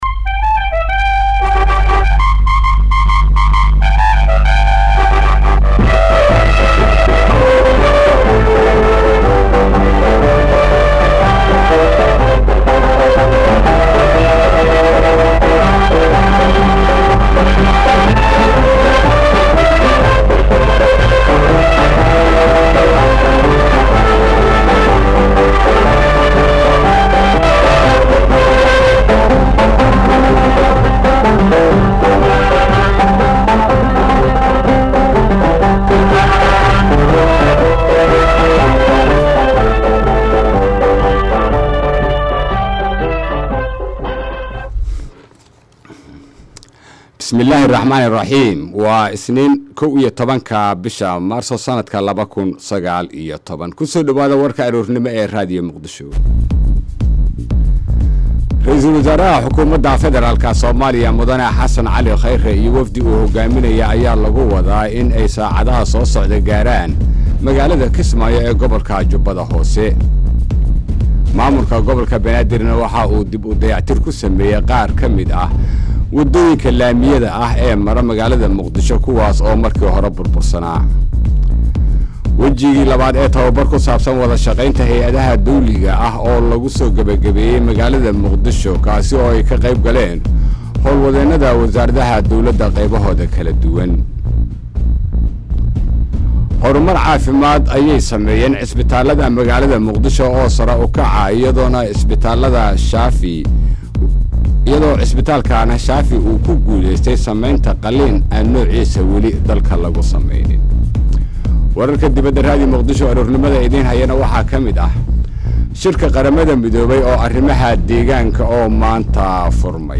Dhageyso Warka Subax ee Radio Muqdisho.